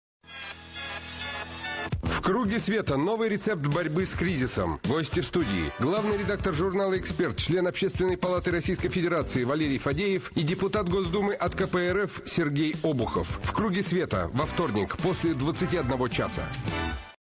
Аудио: анонс –